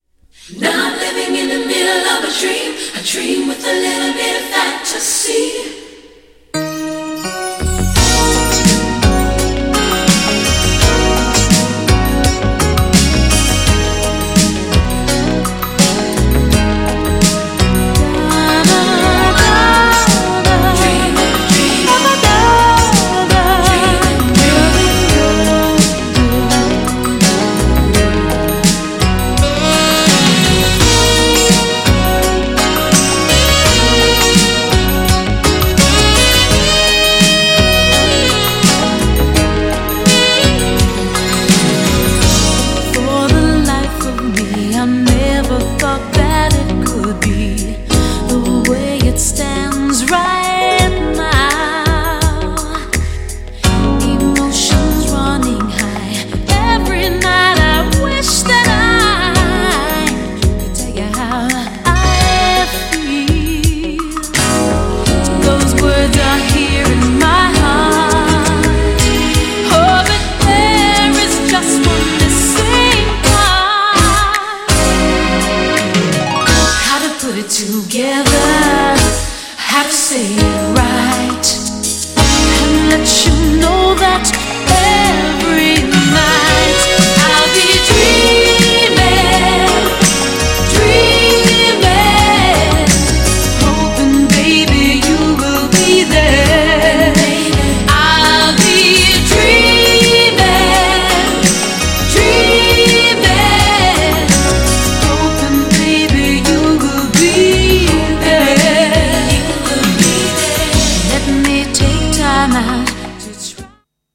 GENRE Dance Classic
BPM 96〜100BPM
# スロー # ブラコン # メロウ # 切ない感じ